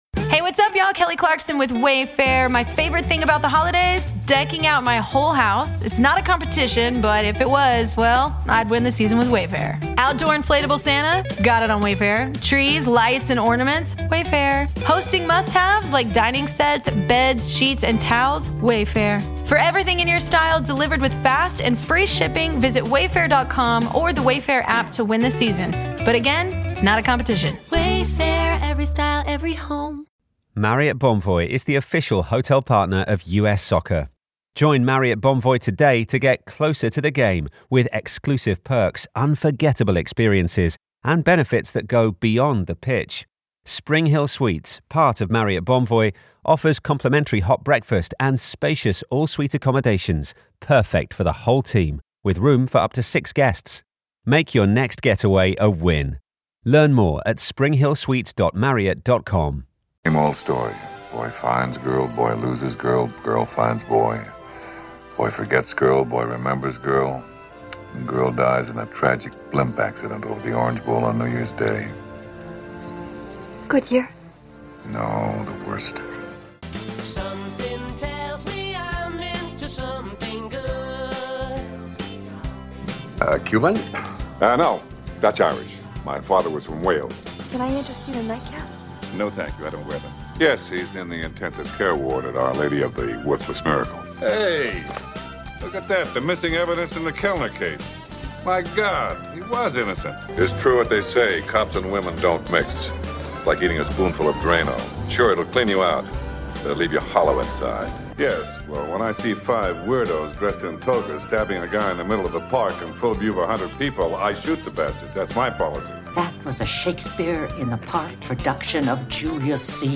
Trivia Game Show